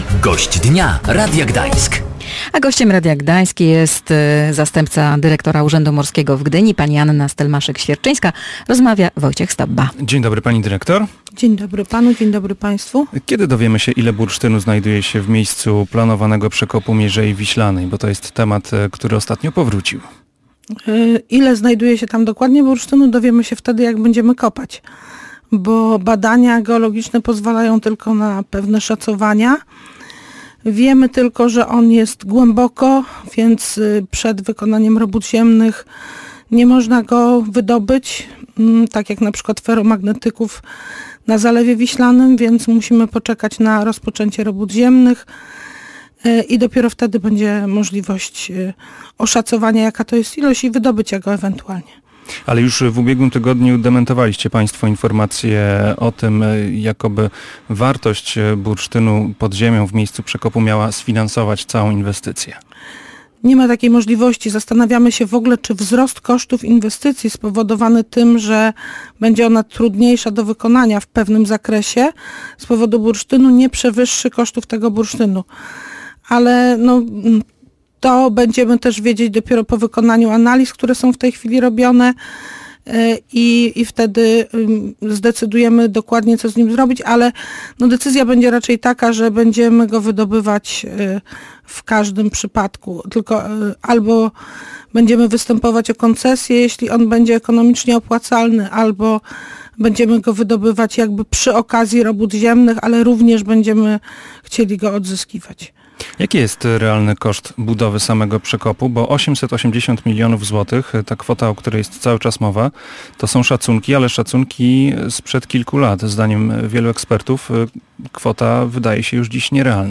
O tym i innych aspektach przekopu mówiła w Gościu dnia